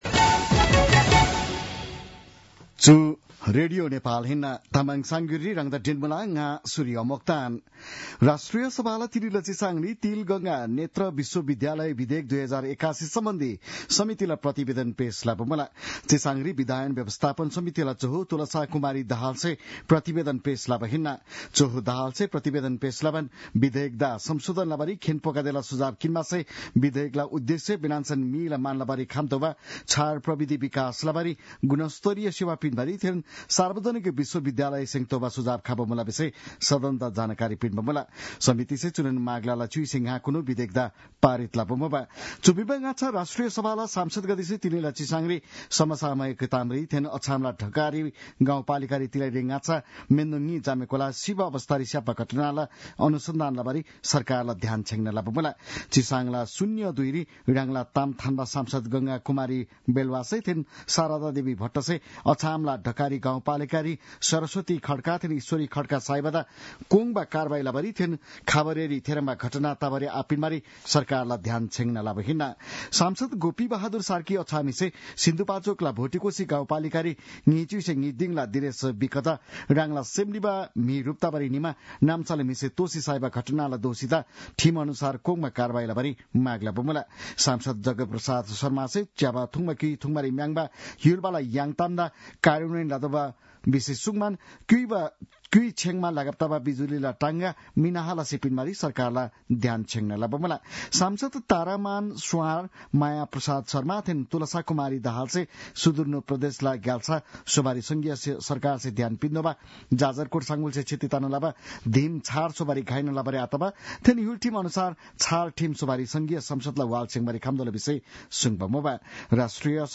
तामाङ भाषाको समाचार : २१ माघ , २०८१
Tamang-news-10-21.mp3